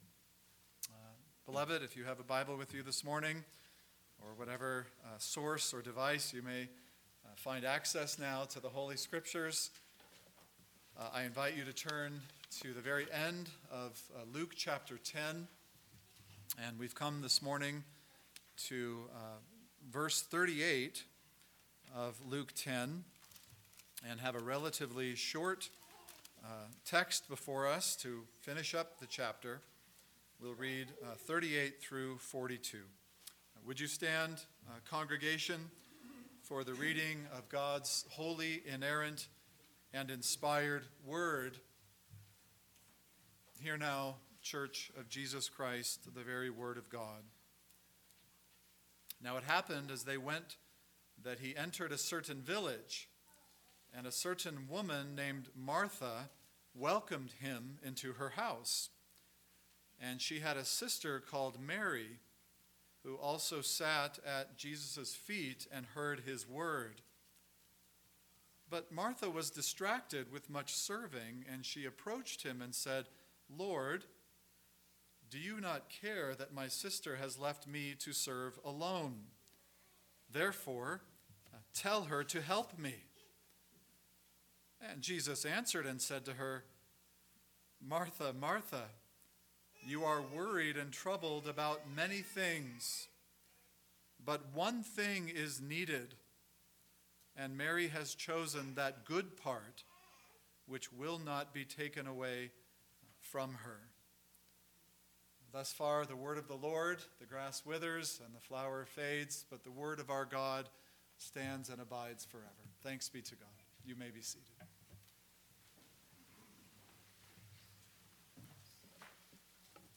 Here are both the video and audio recordings from the sermon: